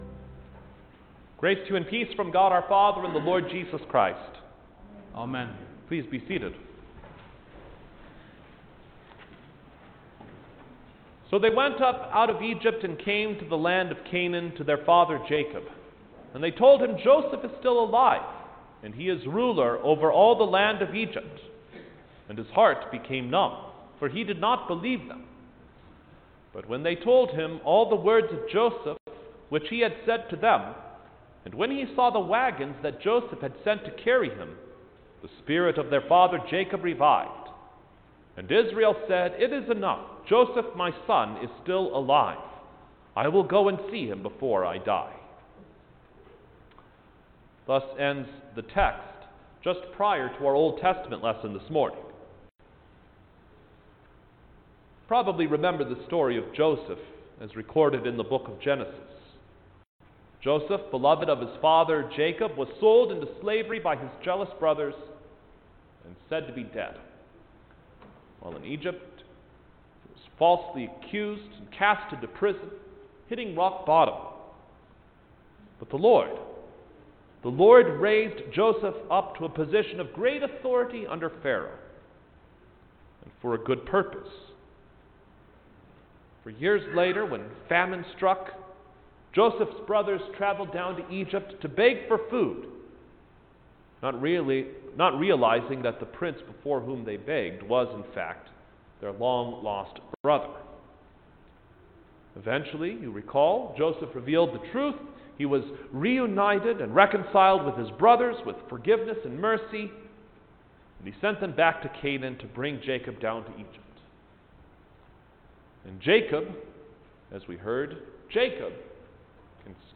January-3_2020-Second-Sunday-After-Christmas-Sermon.mp3